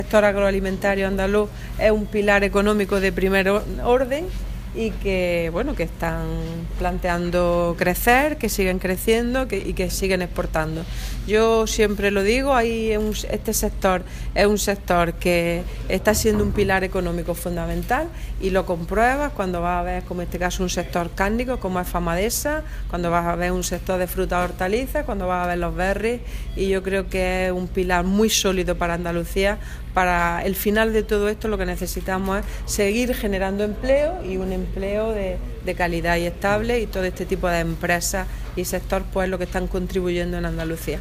Declaraciones consejera sector agroalimentario